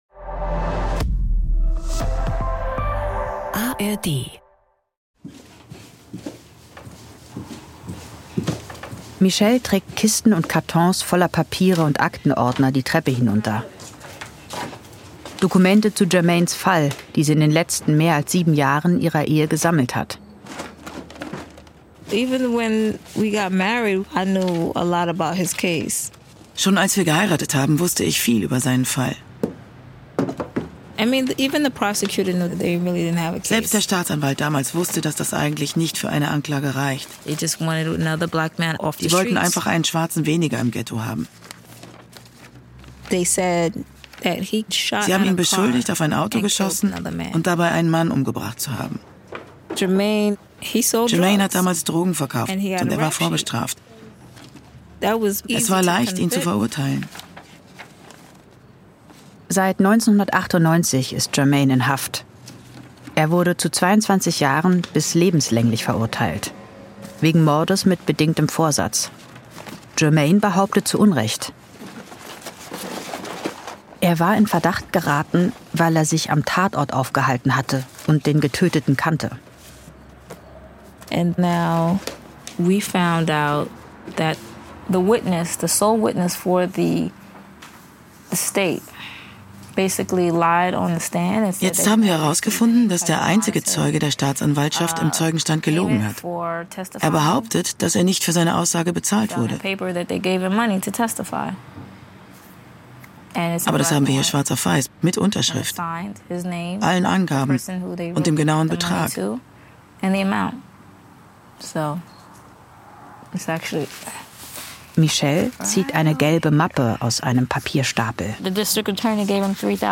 eine True Crime-Story aus konsequent weiblicher Perspektive.
(Stimme der) Erzählerin